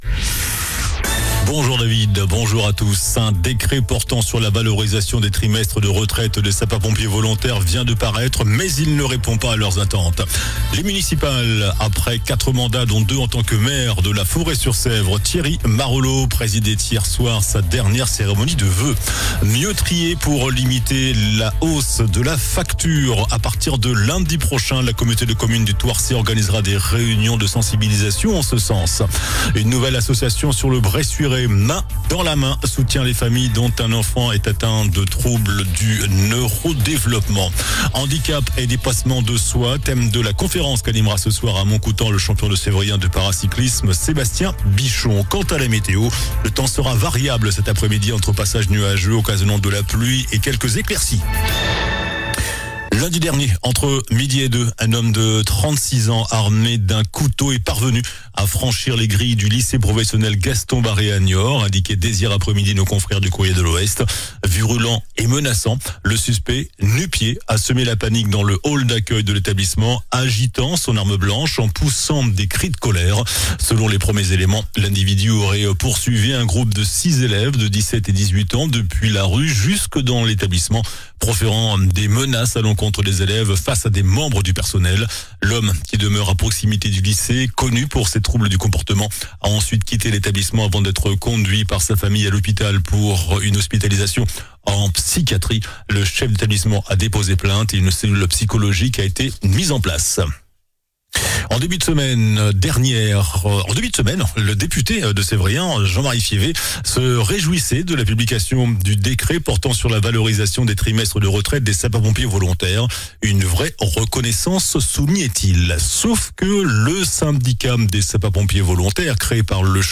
JOURNAL DU VENDREDI 23 JANVIER ( MIDI )